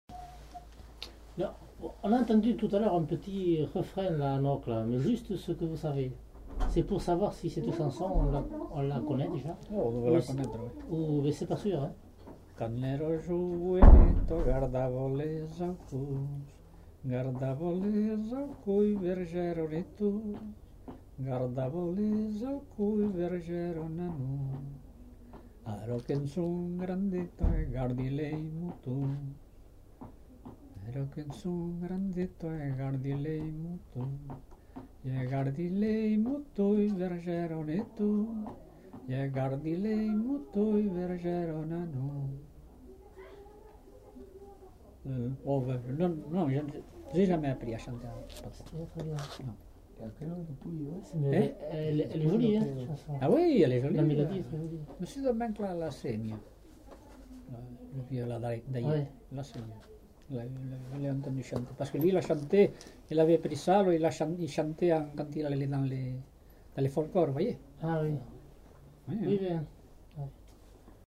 Aire culturelle : Couserans
Lieu : Arrien-en-Bethmale
Genre : chant
Effectif : 1
Type de voix : voix d'homme
Production du son : chanté